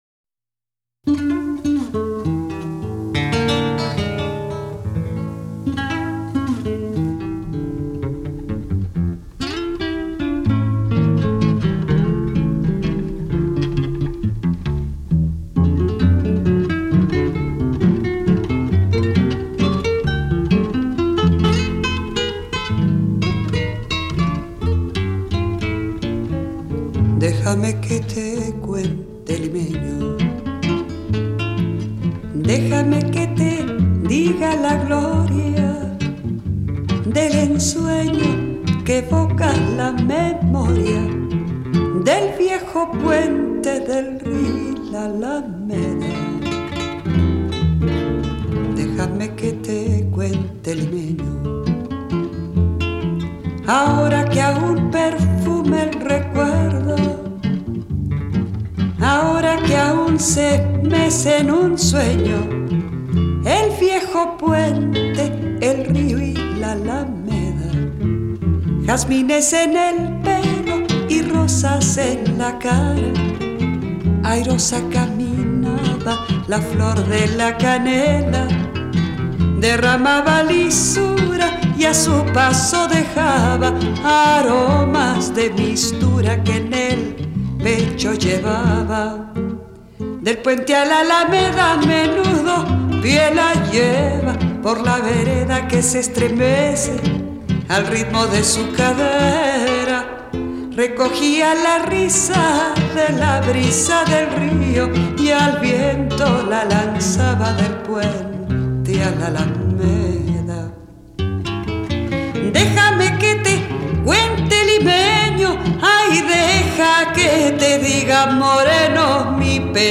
Cancion Española